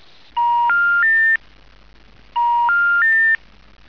error.wav